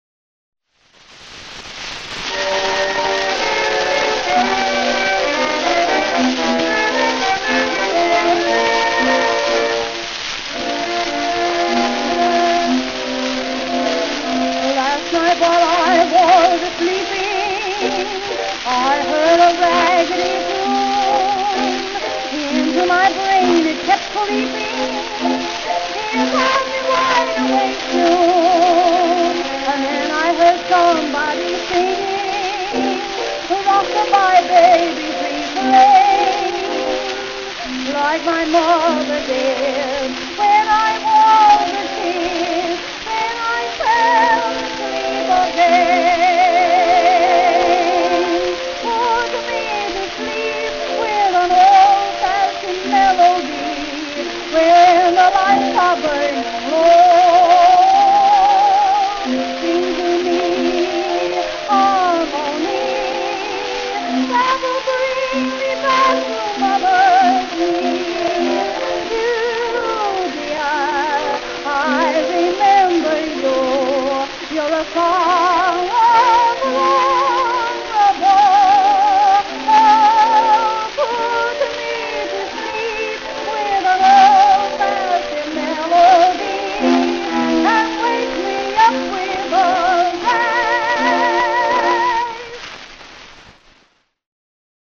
Soprano Solo